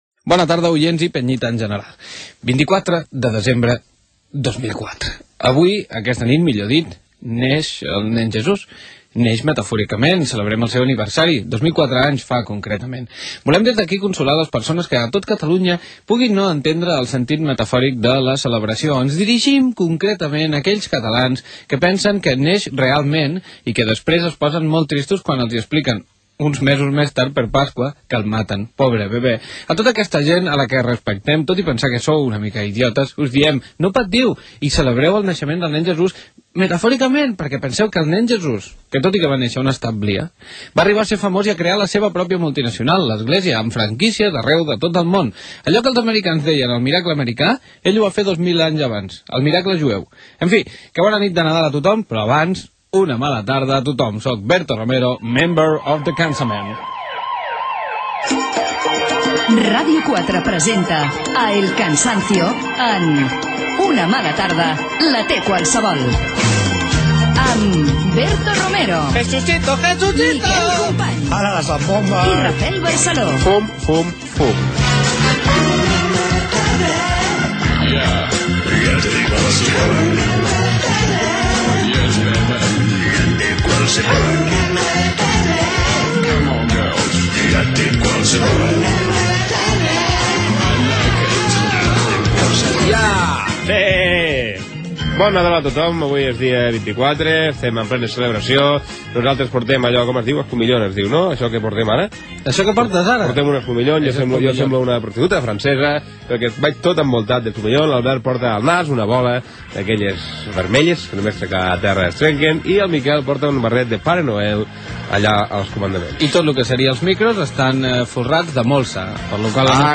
Data, la nit de Nadal i el nen Jesús, careta del programa, el guarnit de l'estudi i el pessebre del programa, el cansament del Nadal, indicatius del programa, les nadales amb alguns exemples de lletres "estranyes", indicatiu Gènere radiofònic Entreteniment